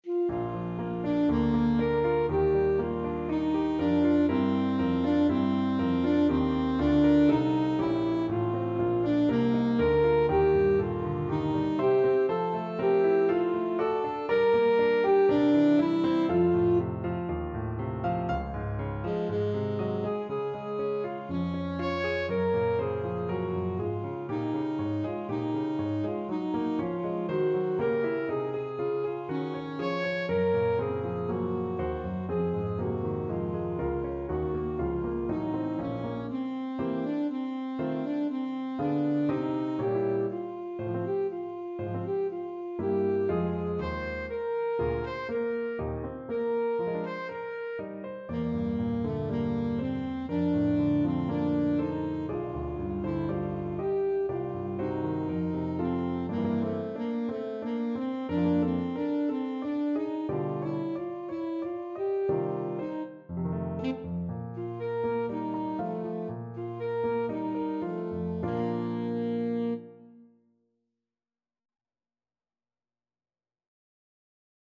Alto Saxophone
4/4 (View more 4/4 Music)
Bb major (Sounding Pitch) G major (Alto Saxophone in Eb) (View more Bb major Music for Saxophone )
Allegro (View more music marked Allegro)
Classical (View more Classical Saxophone Music)
brahms_academic_festival_ASAX.mp3